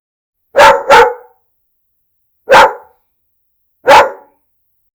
dog_5s.wav